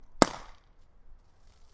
香槟酒
描述：现场录音 在香槟酒瓶上弹出软木塞。 录音设备：变焦记录器H1NLocation：Mountain View，Day of Day：Evening
Tag: 庆祝 香槟 聚会 夜生活 软木 流行音乐 现场录音